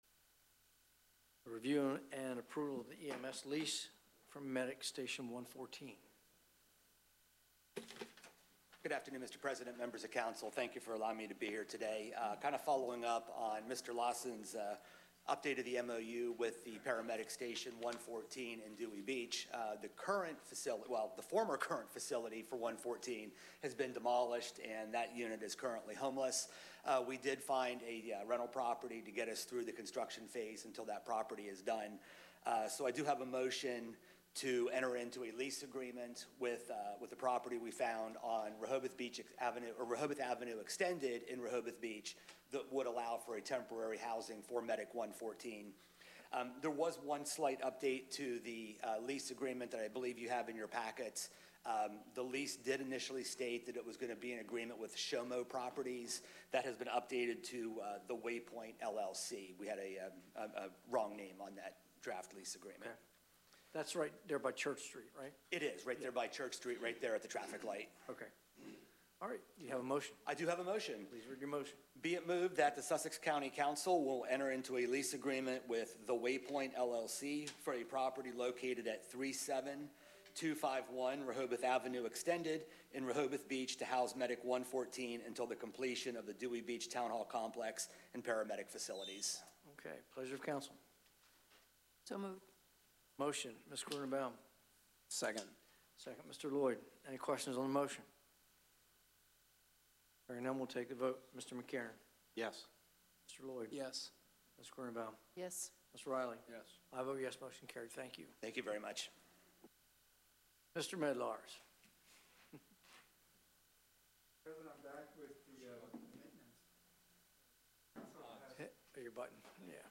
County Council Meeting | Sussex County
Meeting location: Council Chambers, Sussex County Administrative Office Building, 2 The Circle, Georgetown